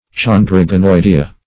Search Result for " chondroganoidea" : The Collaborative International Dictionary of English v.0.48: Chondroganoidea \Chon`dro*ga*noi"de*a\, n. [NL., fr. Gr. cho`ndros cartilage + NL. ganoidei.